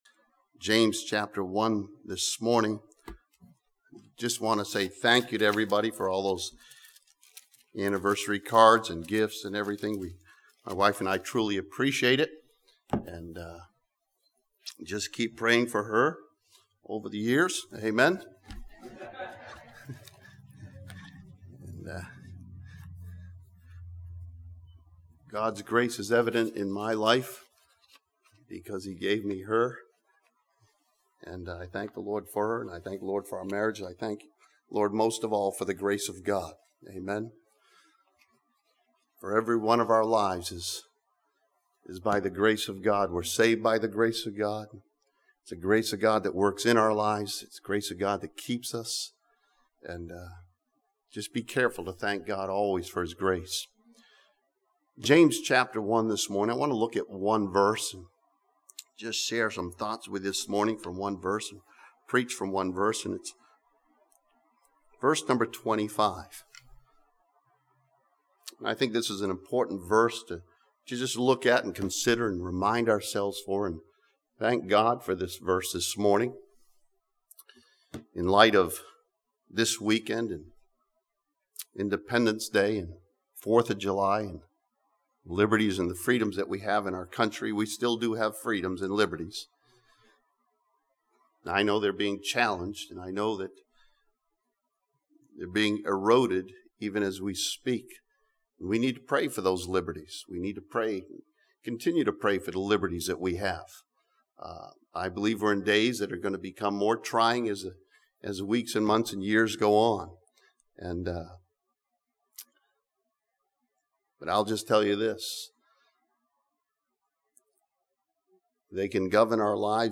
This sermon from James chapter 1 studies the perfect law of liberty that sets us free.